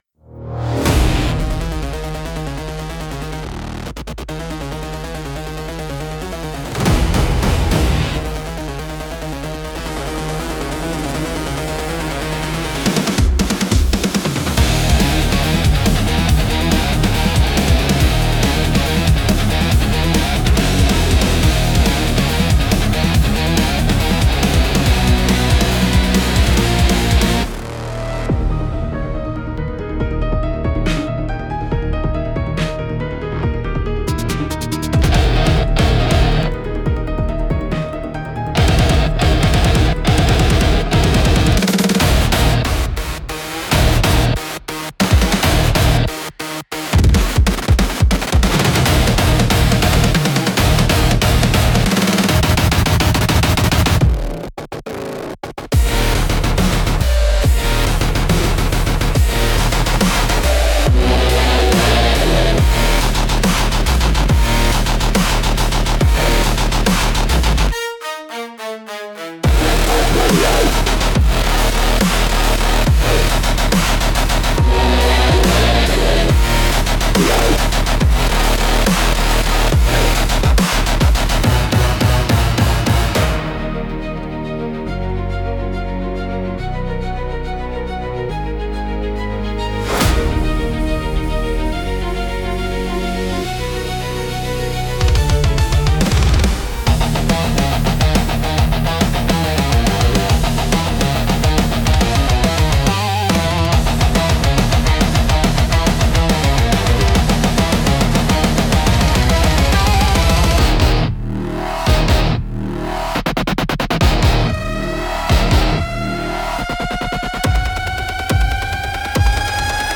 BGM用途としては、サスペンスや戦闘、追跡劇など緊迫したシーンに最適です。